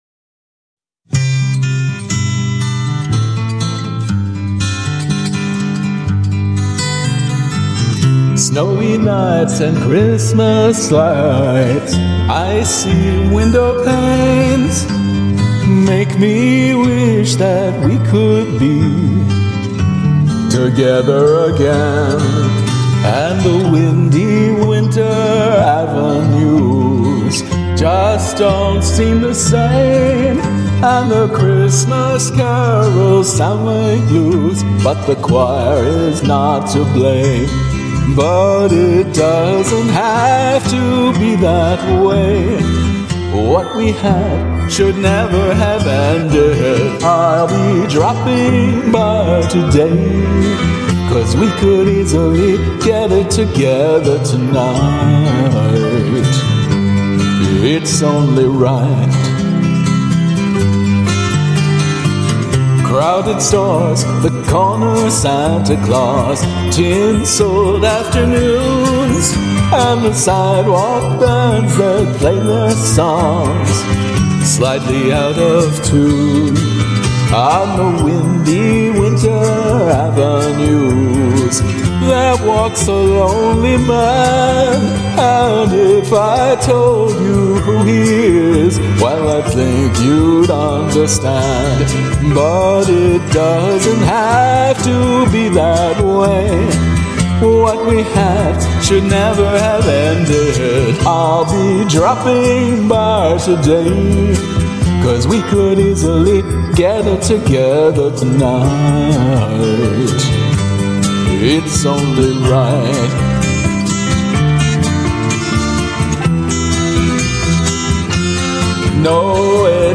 vocals
karaoke arrangement